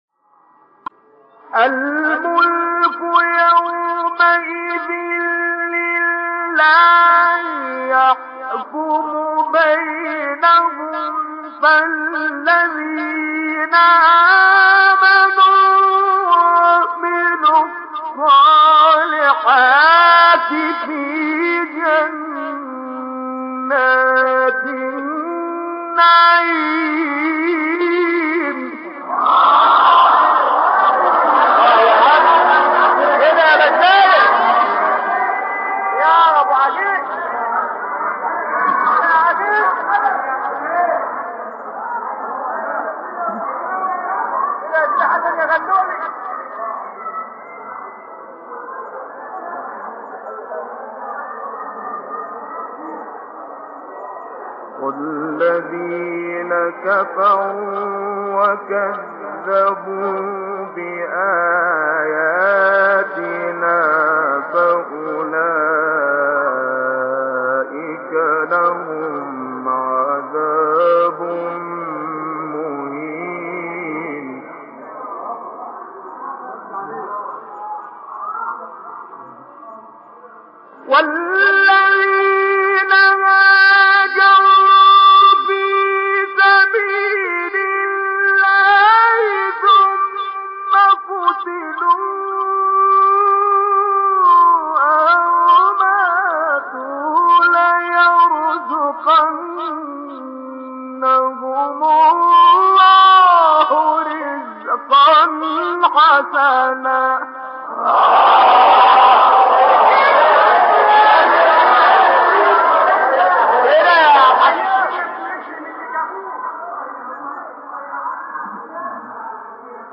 سوره : حج آیه: 56-63 استاد : شعبان صیاد مقام : بیات قبلی بعدی